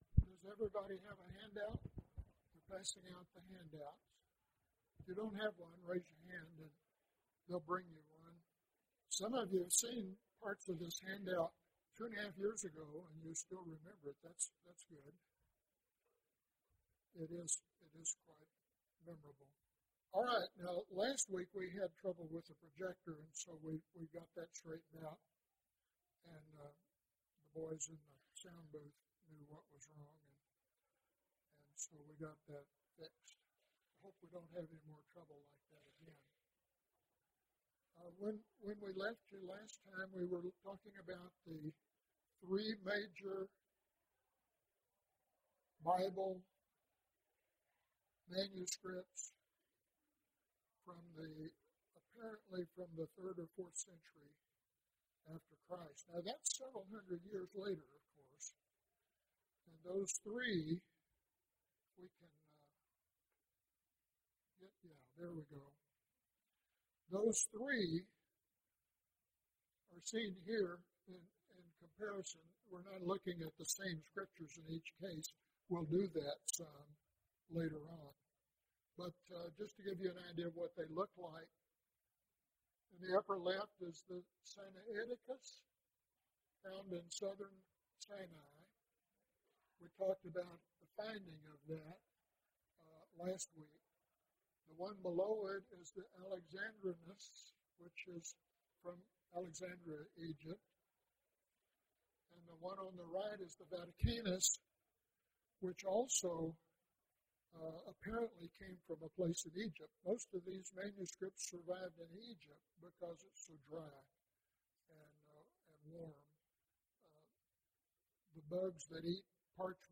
The Discovery of Codex Washingtoneus (6 of 14) – Bible Lesson Recording